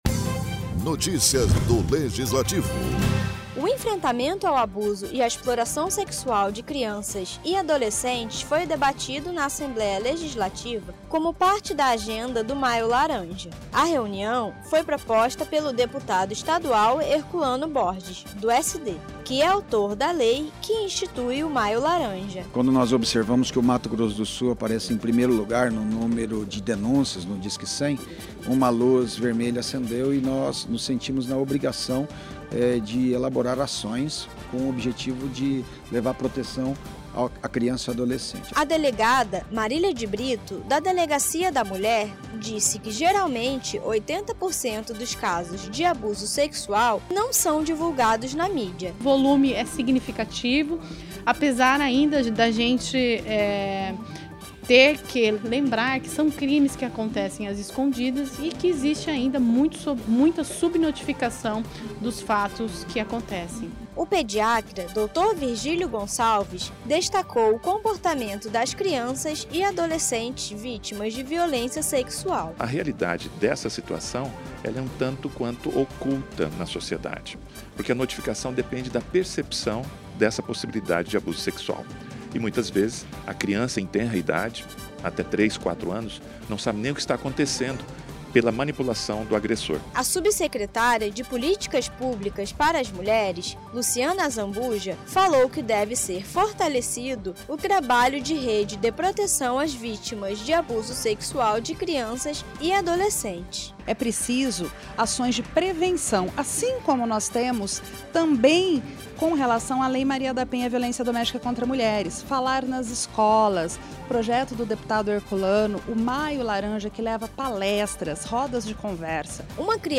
Como parte da agenda do 'Maio Laranja - Contra o Abuso Sexual de Crianças e Adolescentes' e em consonância com as ações do 18 de Maio, Dia Nacional de Combate ao Abuso e Exploração Sexual de Crianças e Adolescentes, a Assembleia Legislativa realizou uma audiência pública para debater o assunto e reforçar esse alerta e as ações de combate.
Locução